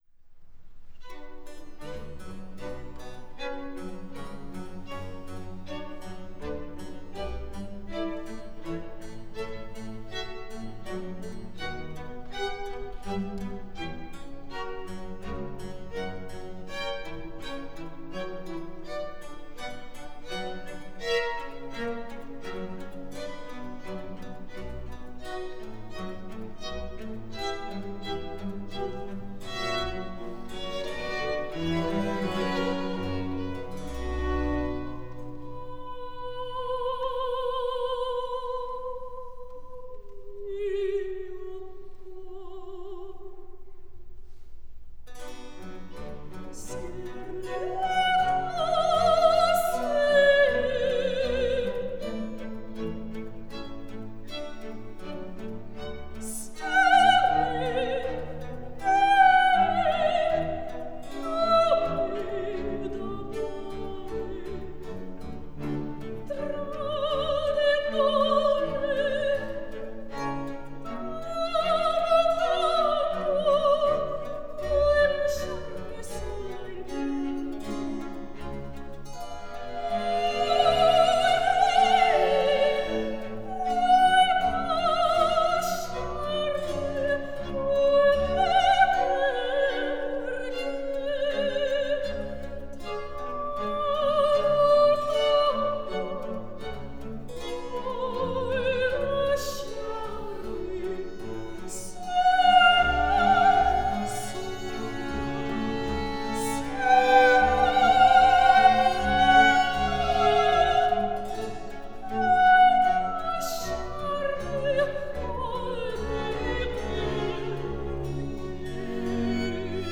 soprano
Solo song: